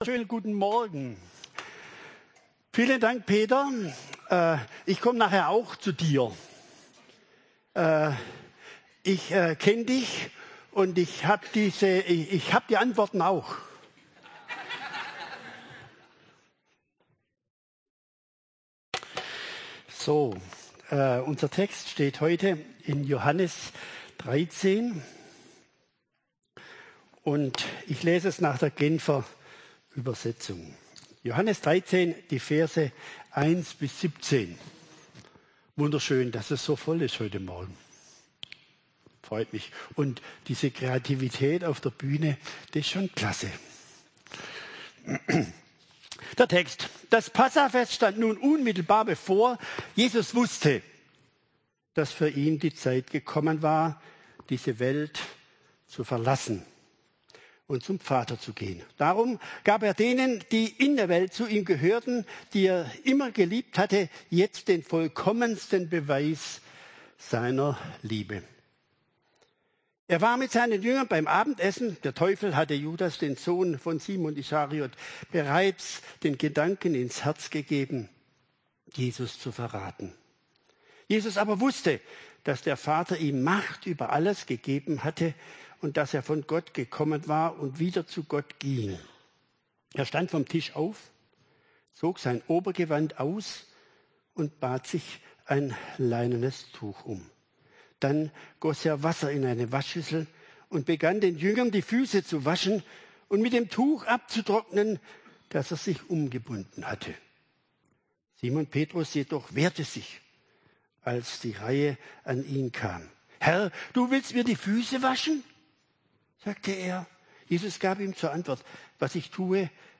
Vormittagsgottesdienst zum Thema: Joh 13,1-17 beim Christusbund Kirchheim unter Teck.